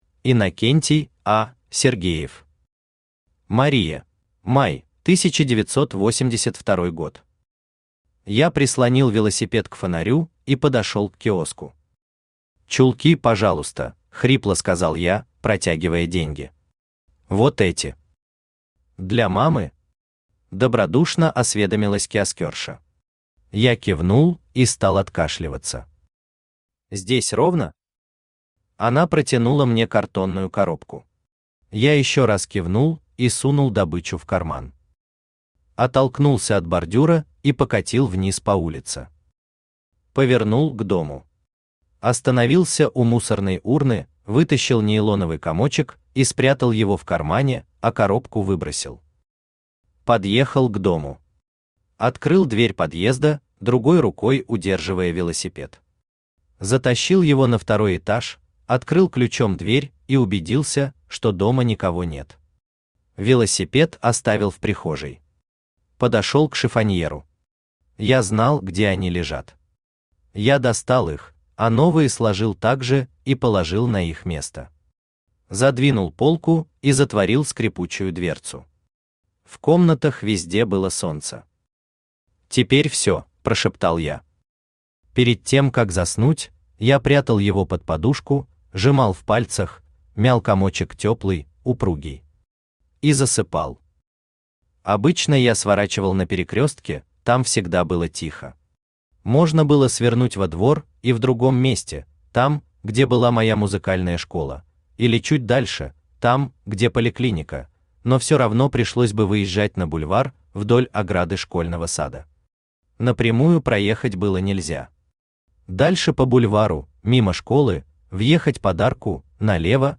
Аудиокнига Мария | Библиотека аудиокниг
Aудиокнига Мария Автор Иннокентий А. Сергеев Читает аудиокнигу Авточтец ЛитРес.